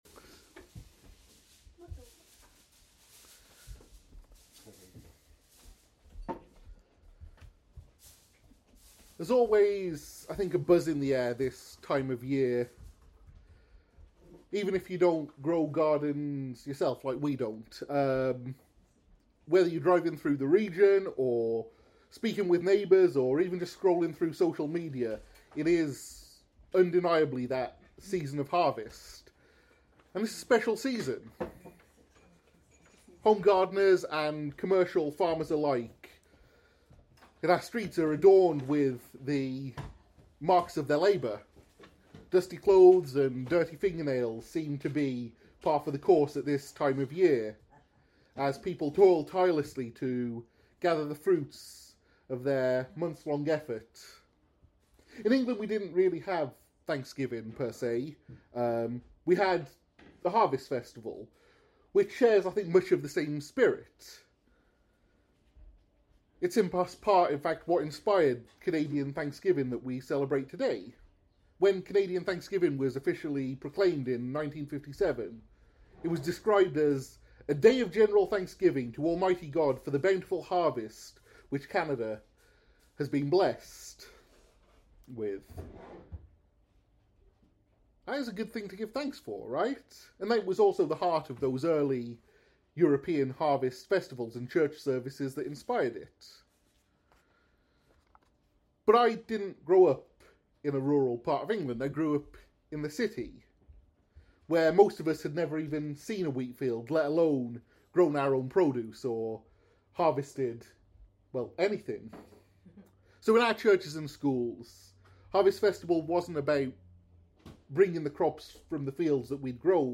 Christian sermon